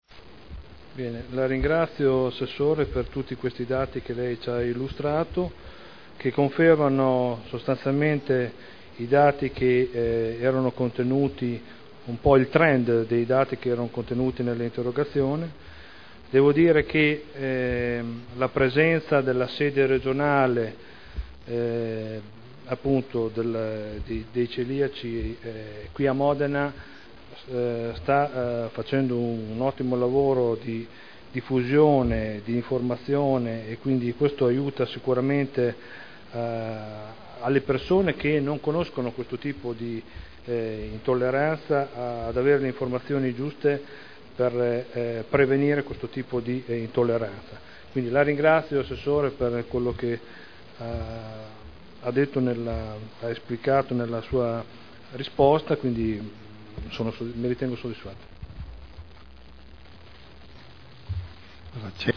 Seduta del 10/01/2011. Conclude interrogazione del consigliere Prampolini (P.D.) avente per oggetto: “Celiachia a Modena”